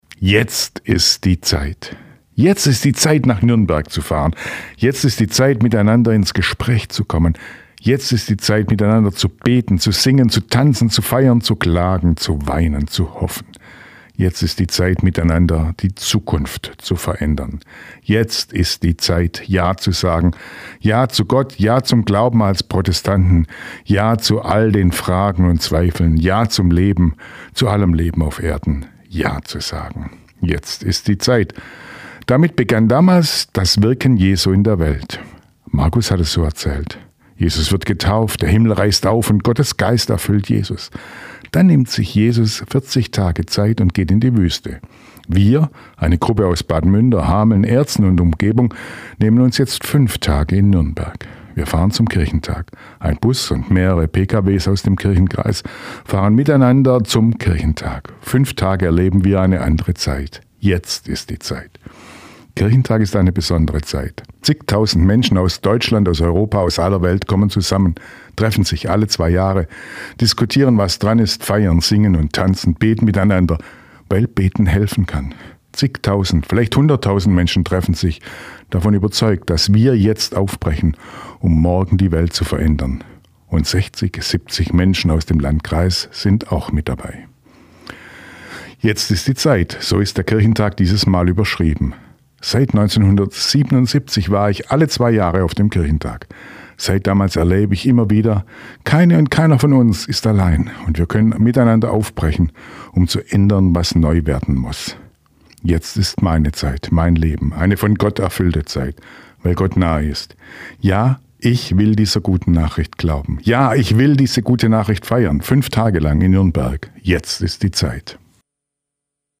Radioandacht vom 6. Juni
radioandacht-vom-6-juni.mp3